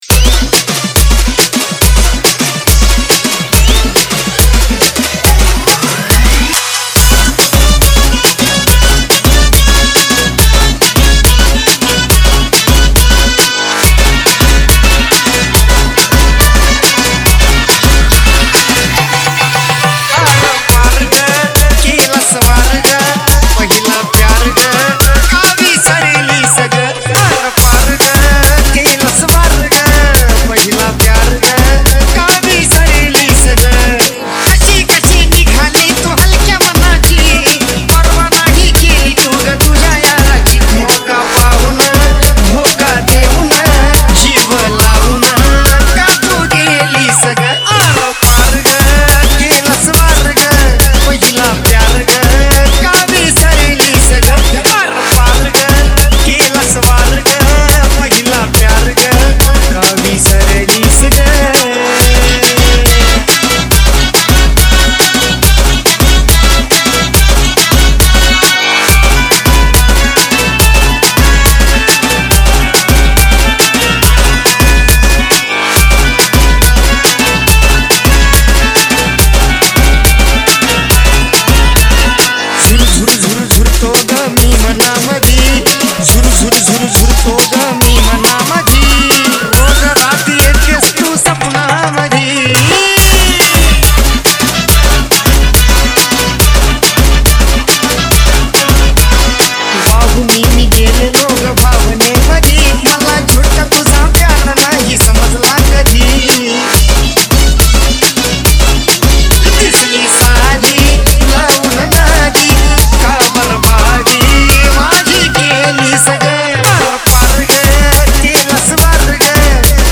MARATHI ALBUM